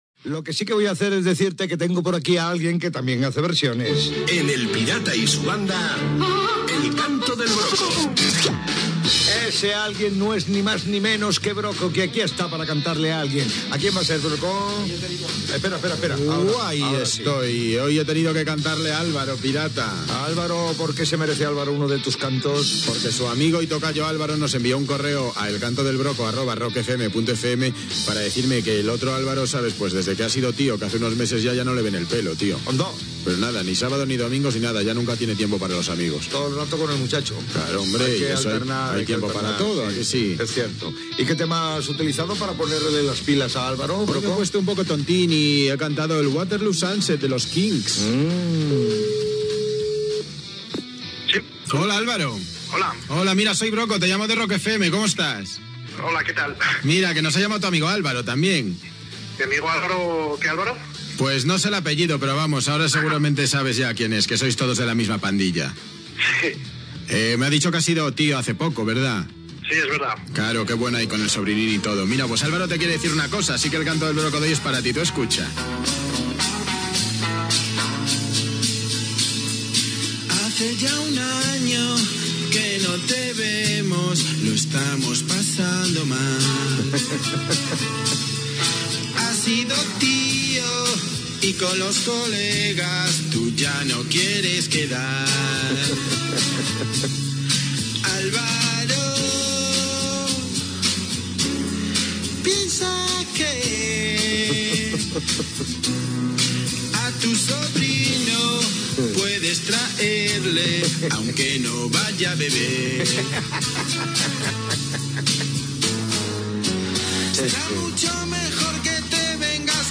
trucada telefònica
Musical